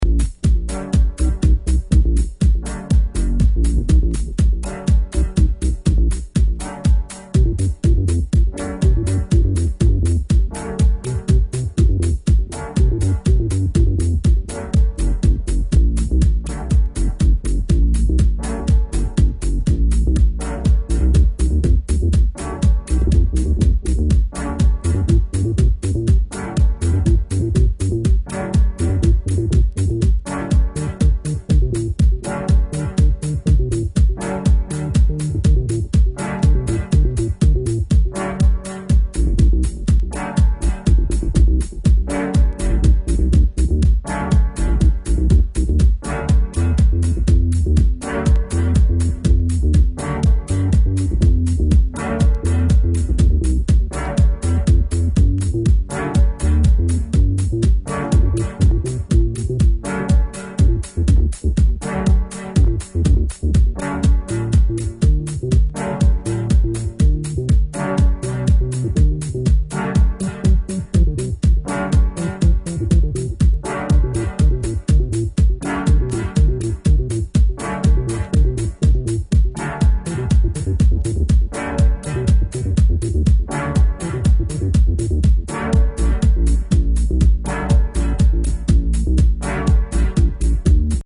NY garage/deephouse